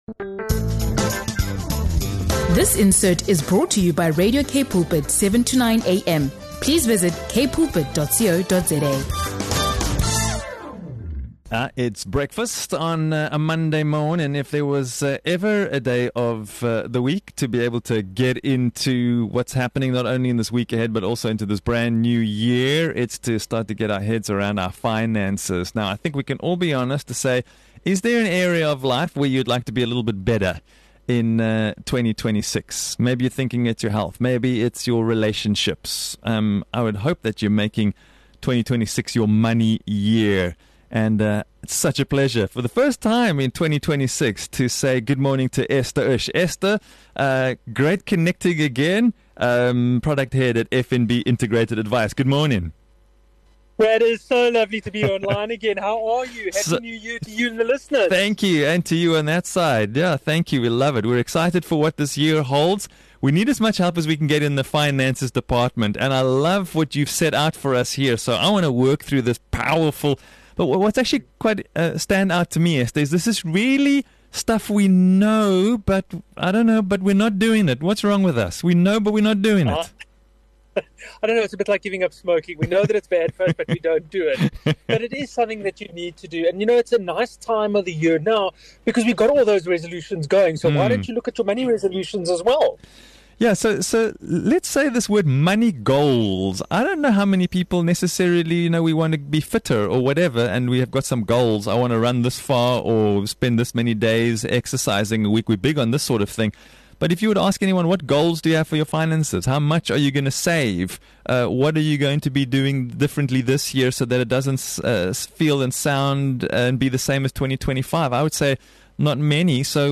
In this powerful and practical conversation